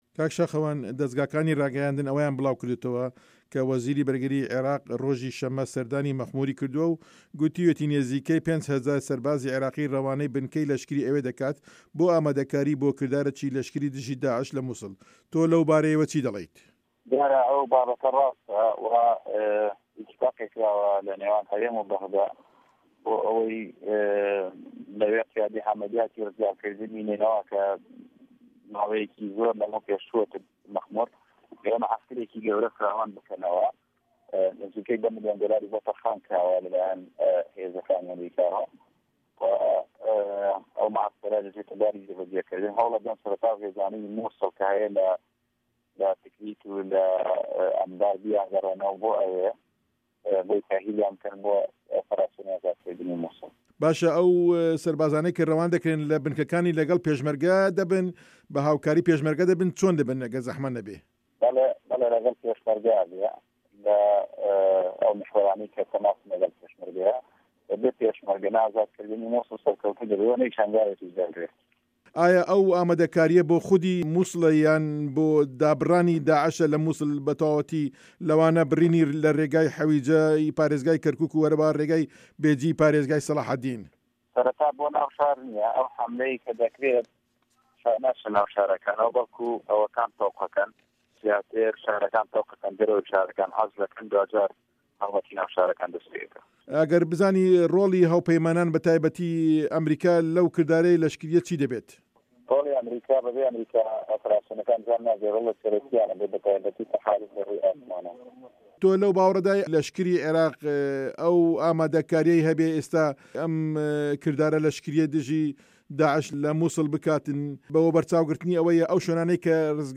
وتووێژ لەگەڵ شاخەوان عەبدوڵڵا